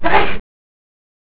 sneezes on the hour every hour.